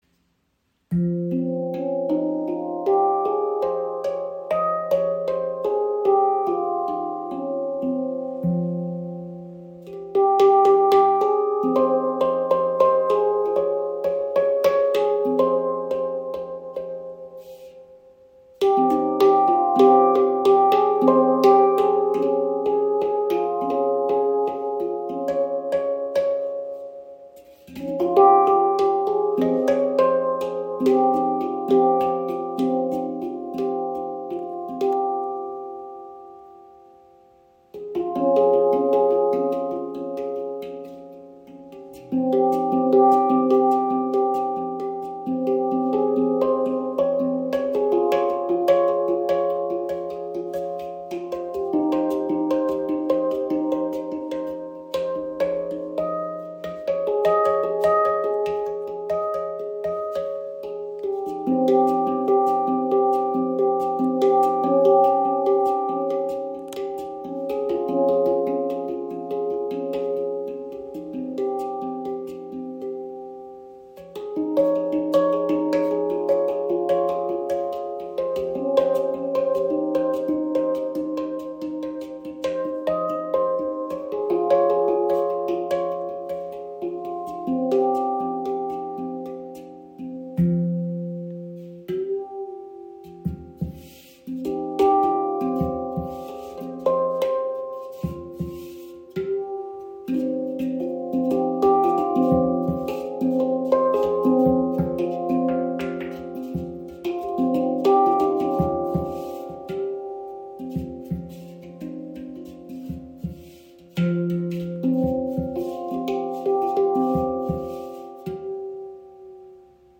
Handpan Chirp | F Integral | 10 Klangfelder – inkl. Rucksacktasche
• Icon F Integral – warme, klare Töne (F – C Db Eb F G Ab C Db Eb)
Die F Integral 10-Stimmung öffnet eine schwebende, harmonische Klangwelt mit sanfter Tiefe und heller Weite.
Handgefertigte Handpan F Integral 10 aus Indien.
Im Spiel zeigt sich die Handpan warm, klar und lebendig, mit angenehmer Dynamik und sehr zugänglicher Ansprache.